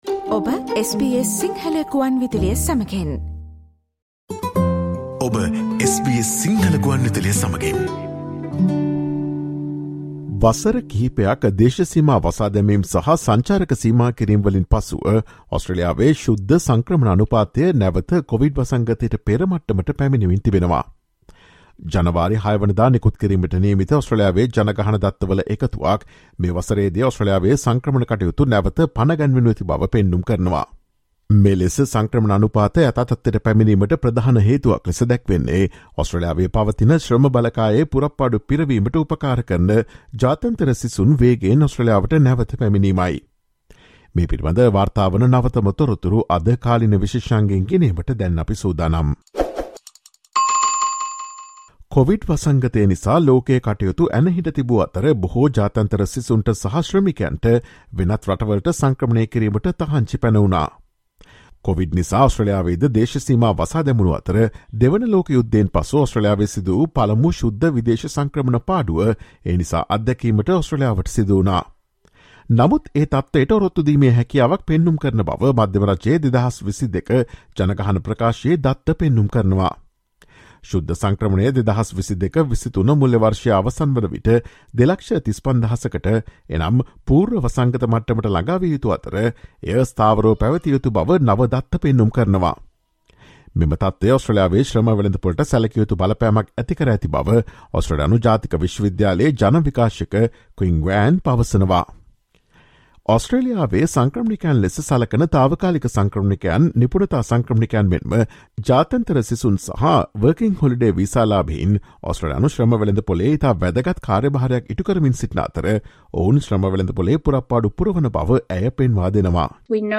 After years of border closures and travel restrictions, Australia's net migration rates are returning to pre-pandemic levels. Listen to the SBS Sinhala Radio's current affairs feature on Thursday, 05 January.